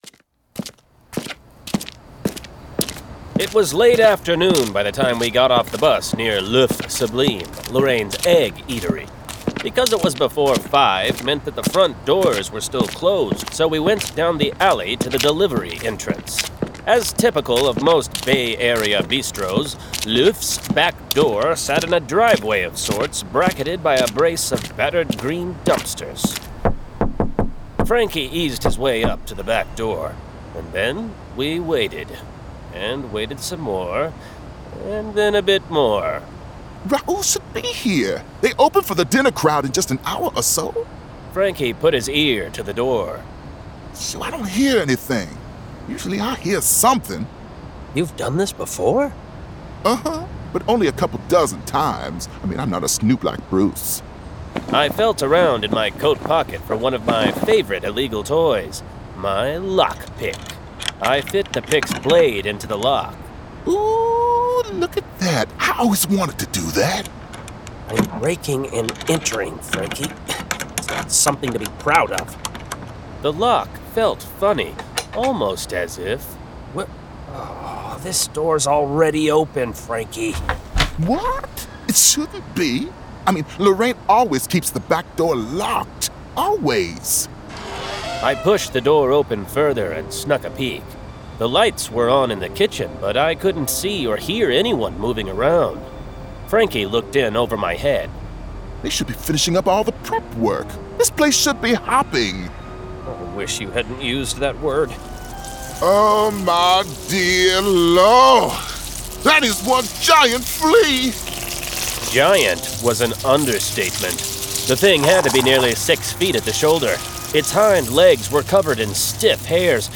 Full Cast. Cinematic Music. Sound Effects.
[Dramatized Adaptation]
Genre: Urban Fantasy